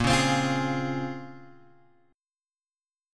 A# Minor 9th
A#m9 chord {6 4 x 5 6 4} chord